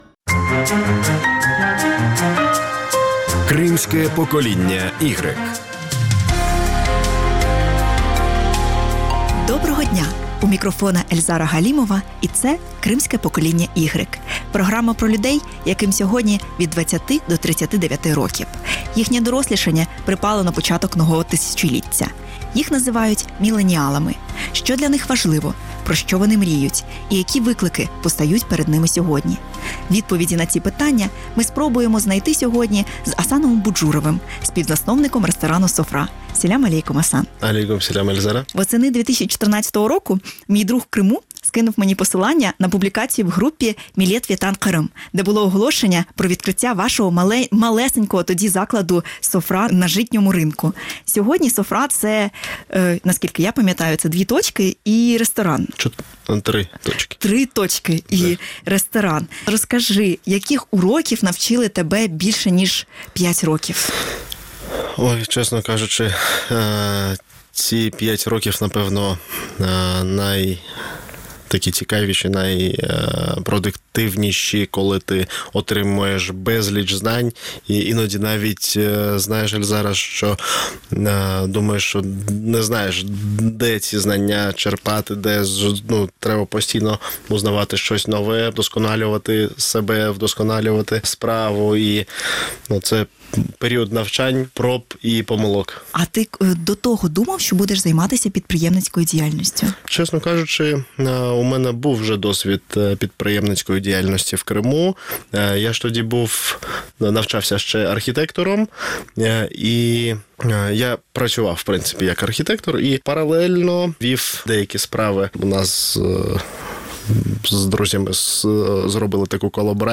У цьому інтерв’ю говоримо про те, як кулінарія може перетворитися з хобі у професійну справу, хто придумує рецепти у меню, та як виглядає день молодого підприємця.